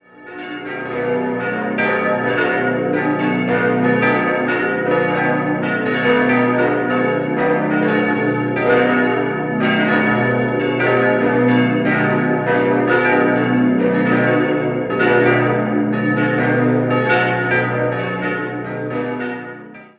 Bei der Stadtpfarrkirche handelt es sich um einen eindrucksvollen neugotischen Bau mit 60 Meter hohem Turm. Das Gotteshaus wurde nach den Plänen des Linzer Dombaumeisters Otto Schirmer errichtet und 1888 eingeweiht. 5-stimmiges Geläut: h°-dis'-fis'-a'-cis'' Die Glocke 4 wurde 1923, die anderen 1950 von Oberascher in Salzburg gegossen.